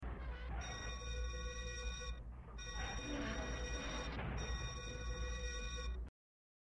The Black Hole FX - Structural overload warning bell
The_Black_Hole_FX_-_Structural_overload_warning_bell.mp3